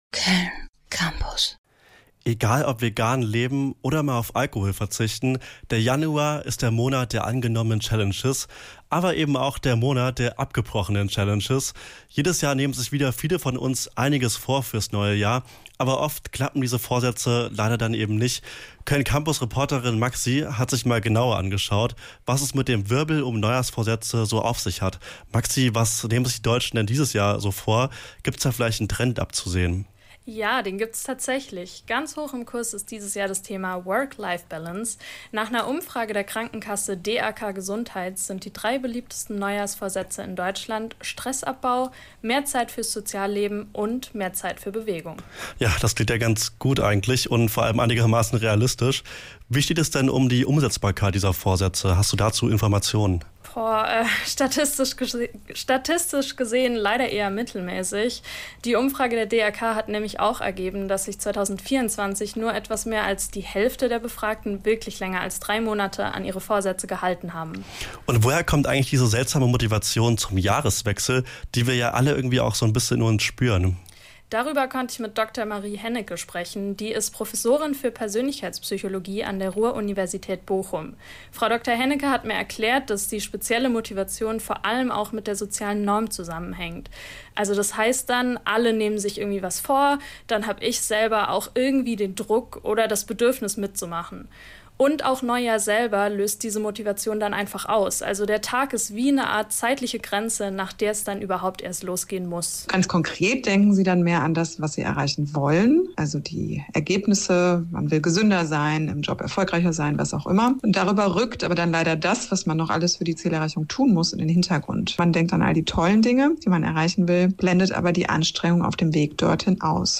Zum Radiobeitrag im Campusradio der Universität zu Köln)